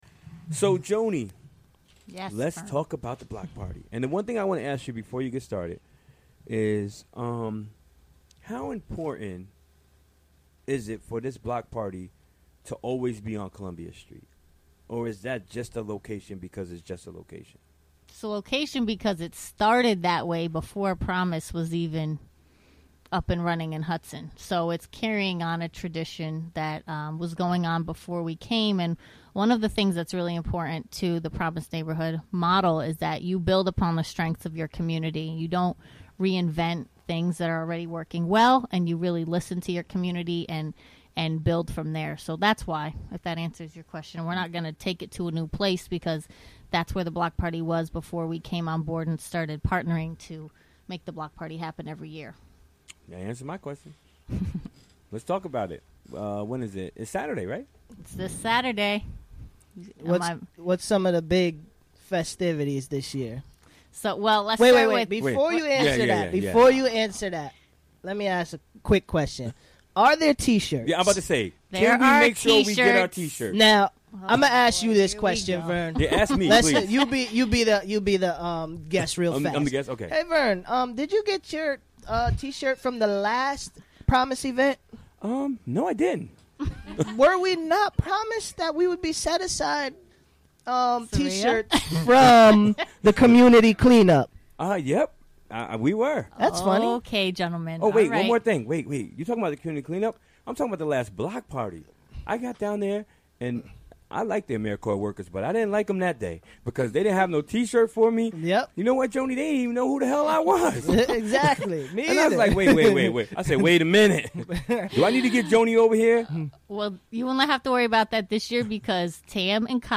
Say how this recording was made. Recorded during the WGXC Afternoon Show Wednesday, August 24, 2016.